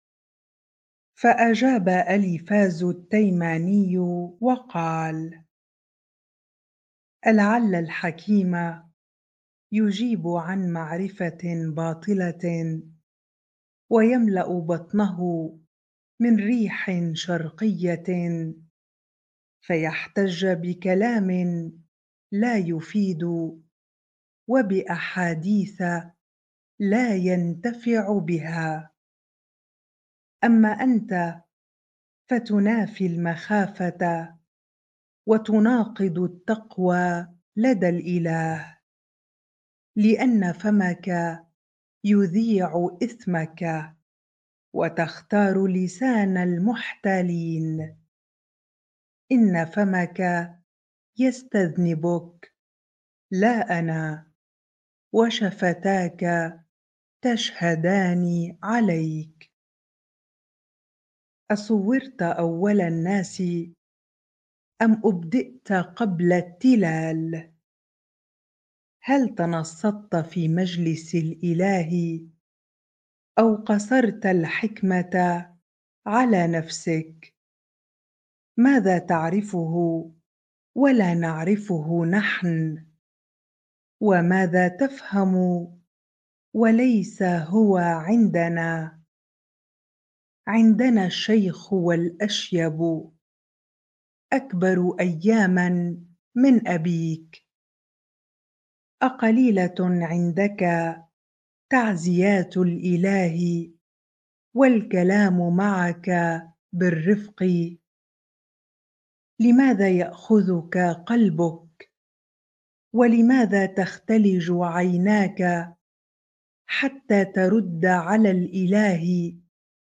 bible-reading-Job 15 ar